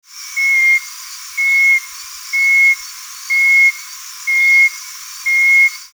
Play Especie: Cophyla phyllodactyla Género: Cophyla Familia: Microhylidae Órden: Anura Clase: Amphibia Título: The calls of the frogs of Madagascar.
Localidad: Madagascar: Localidad tipo Nosy Be
Las frecuencias por debajo de 1000 Hz han sido filtradas.
68 Cophyla Phyllodactyla.mp3